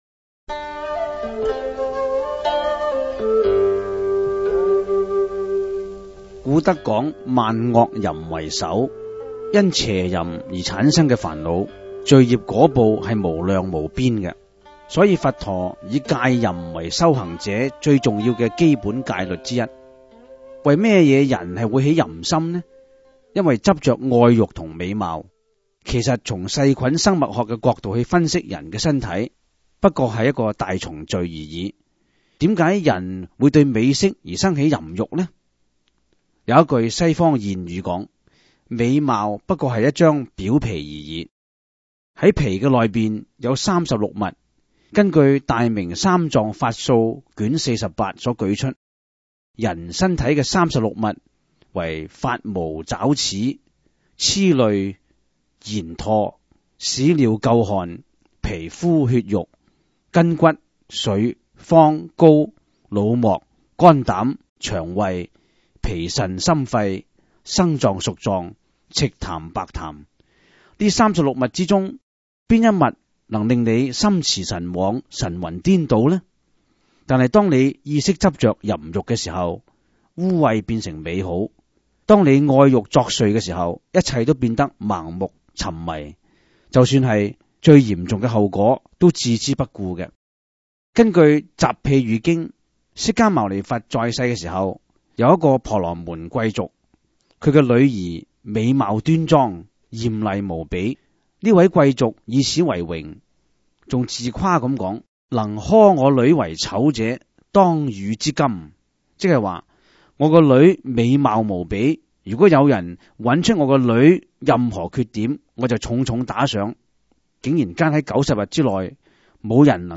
第 十 四 辑          (粤语主讲  MP3 格式)